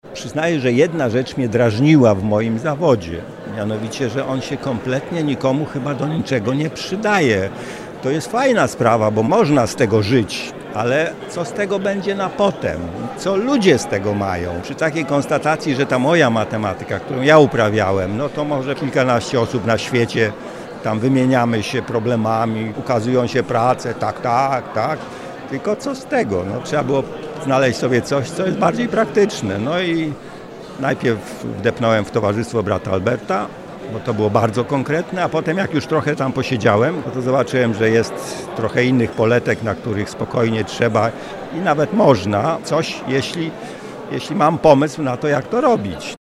We wrocławskim Ratuszu odbyła się promocja książki pt. „Urządzamy Wrocław na nowo. Rada Miejska Wrocławia 1990-1994”.